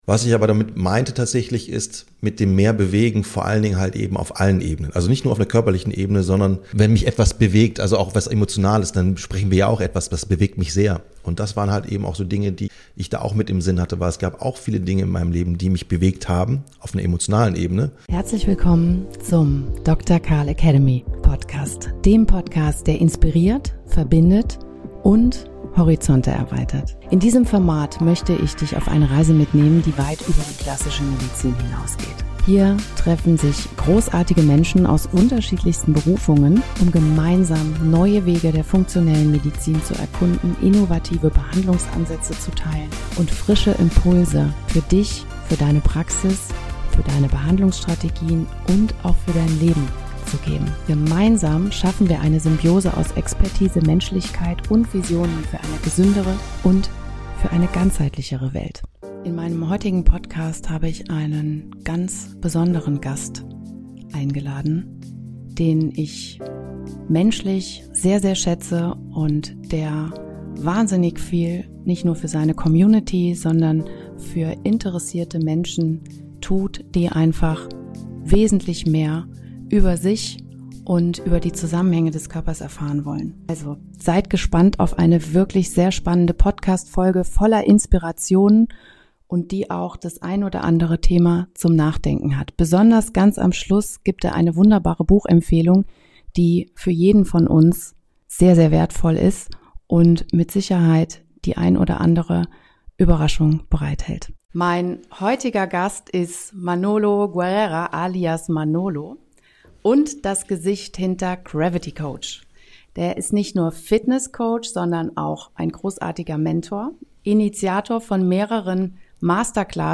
Die beiden Experten betonen die Notwendigkeit, in der heutigen Zeit Netzwerke zu bilden und interdisziplinär zu arbeiten, um den Herausforderungen einer chronisch kranken Gesellschaft zu begegnen. Sie diskutieren die Bedeutung von Bewegung als Schlüssel zur Veränderung und die Rolle der Intuition in der Behandlung.